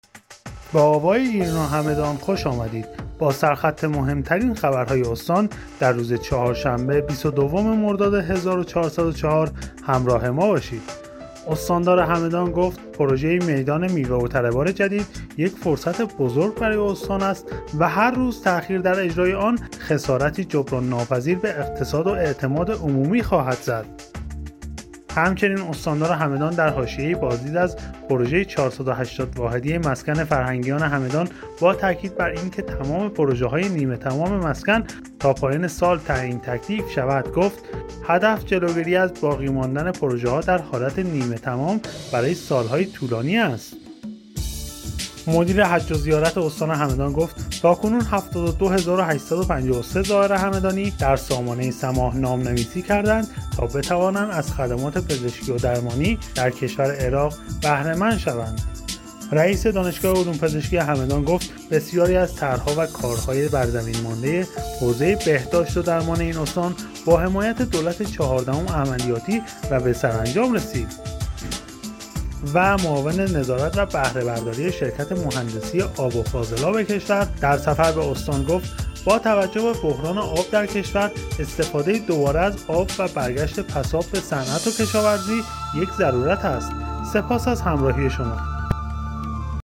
همدان- ایرنا- مهم‌ترین عناوین خبری دیار هگمتانه را هر شب از بسته خبر صوتی آوای ایرنا همدان دنبال کنید.